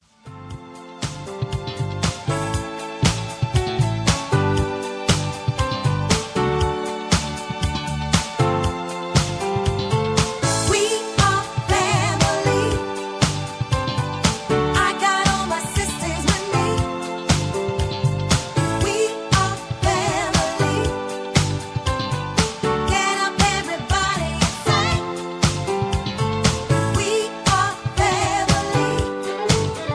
Key-A) Karaoke MP3 Backing Tracks
Just Plain & Simply "GREAT MUSIC" (No Lyrics).